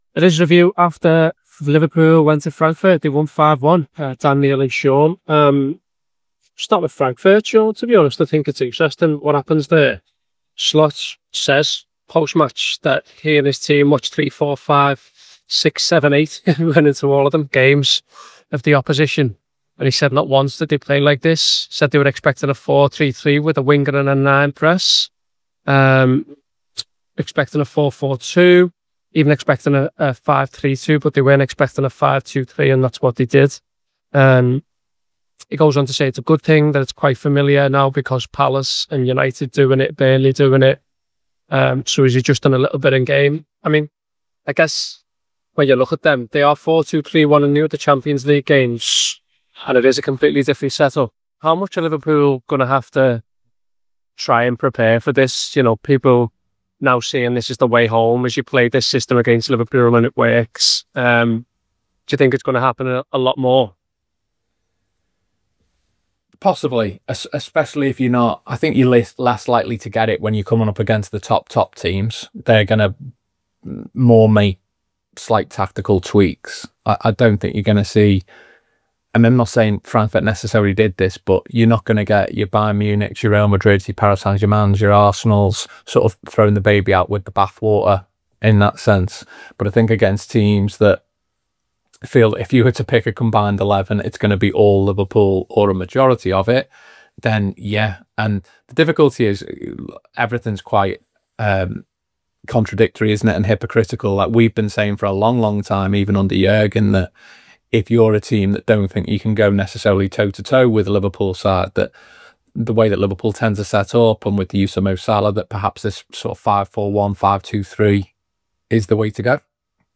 Below is a clip from the show- subscribe for more review chat around Eintracht Frankfurt 1-5 Liverpool…